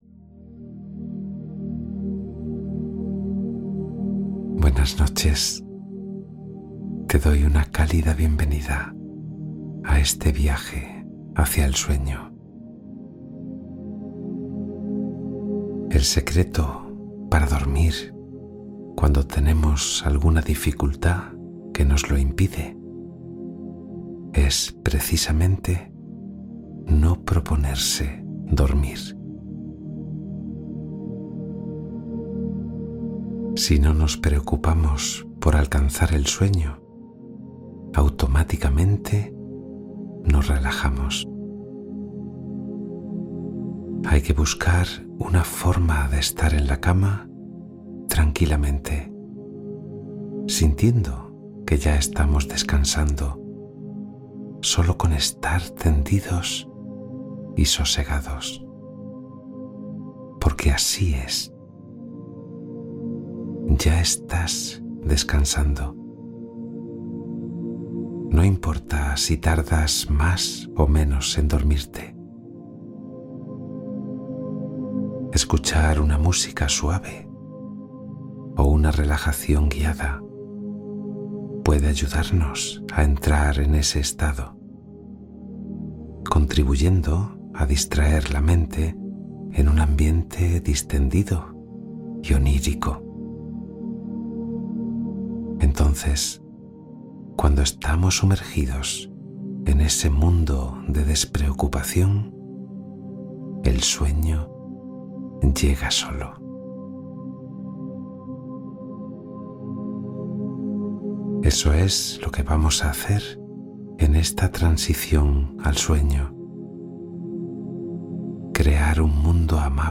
Relato Guiado de Transición para Quedarte Dormida en Pocos Minutos